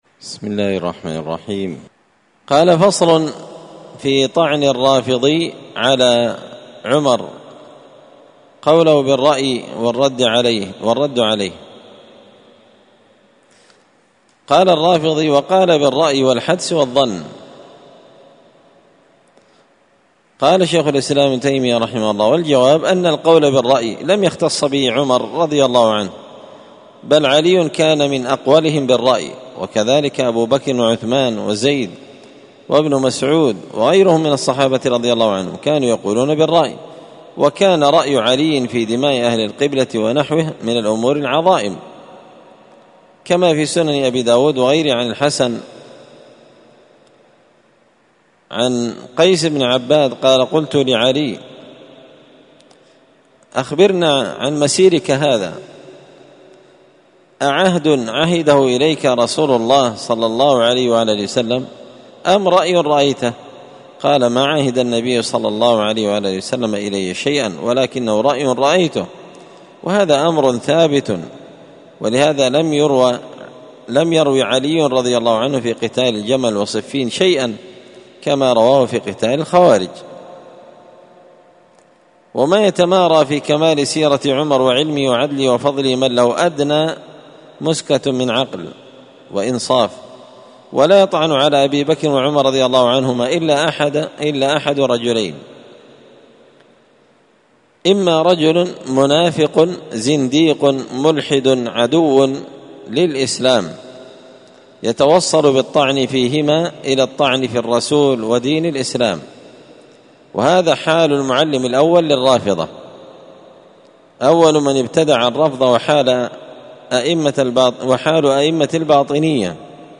الخميس 18 ذو الحجة 1444 هــــ | الدروس، دروس الردود، مختصر منهاج السنة النبوية لشيخ الإسلام ابن تيمية | شارك بتعليقك | 13 المشاهدات